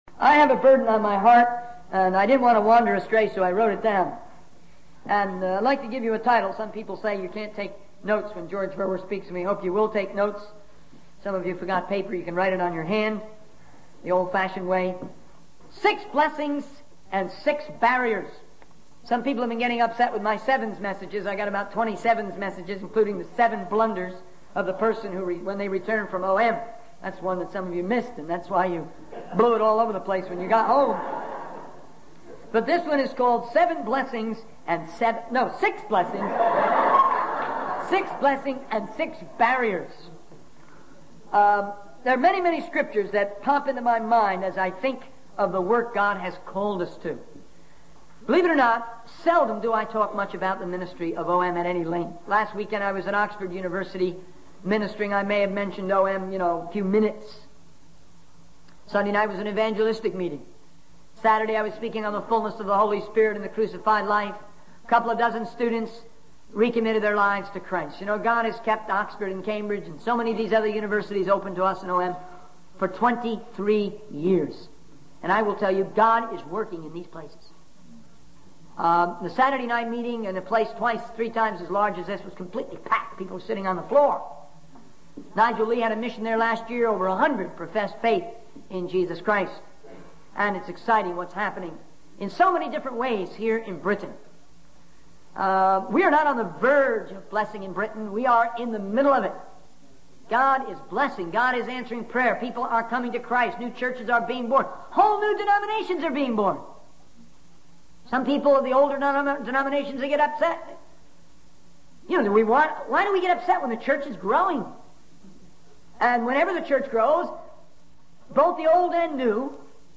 In this sermon, the speaker discusses six blessings and six barriers in the work of OM (Operation Mobilization). The first blessing is the power of the Holy Spirit, which enables believers to accomplish great things.